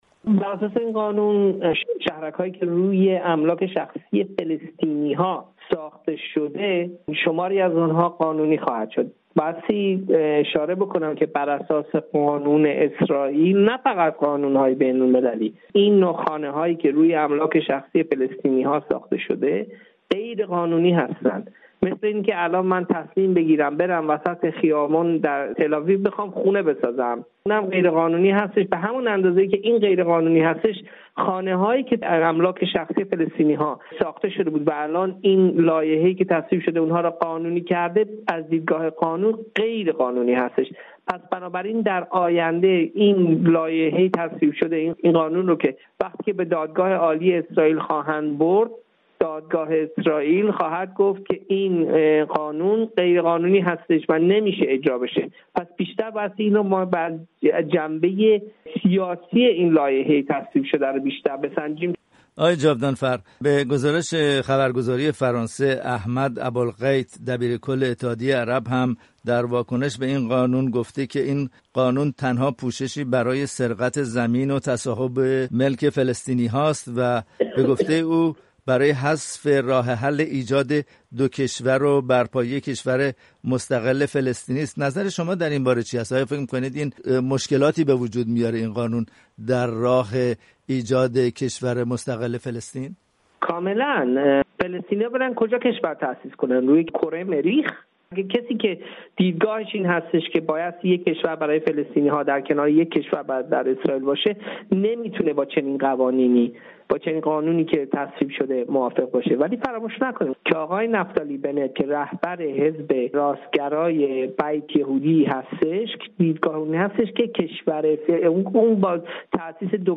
گفتگوی
تحلیلگر سیاسی در اسرائیل